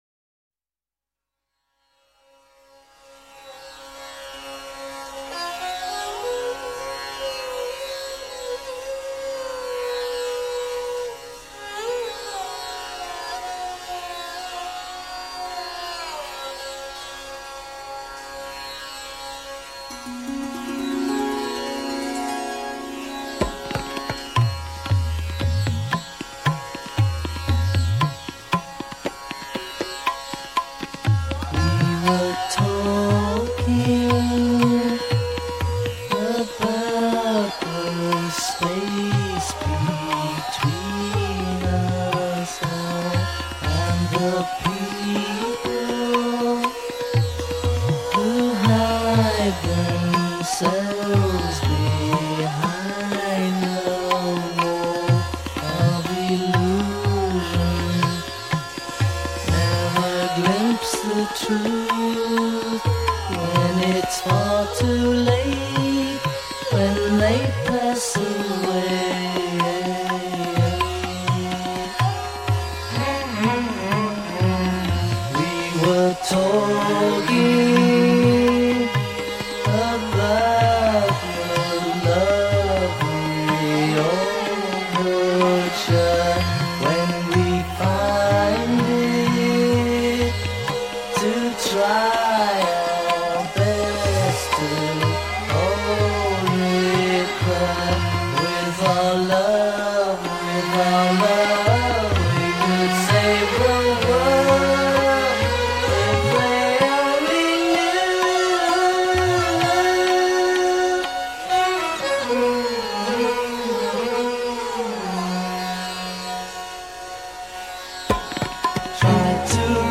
sitar